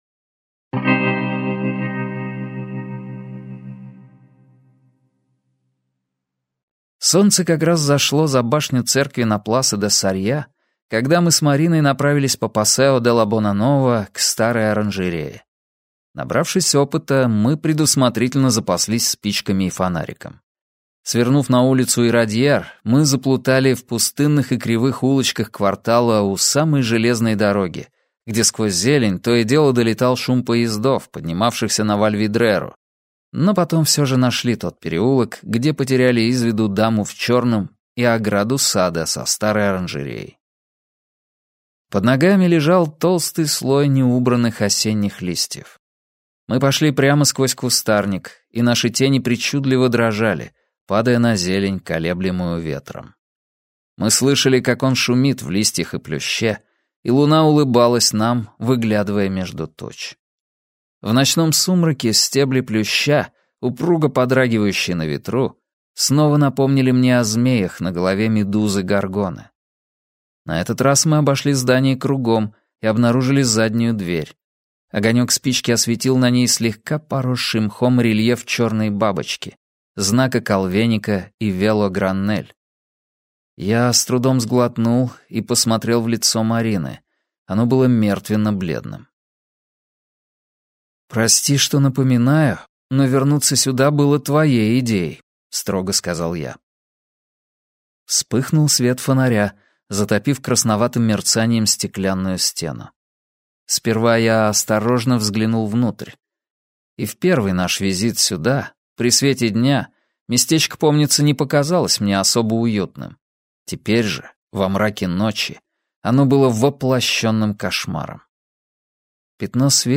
Аудиокнига Марина - купить, скачать и слушать онлайн | КнигоПоиск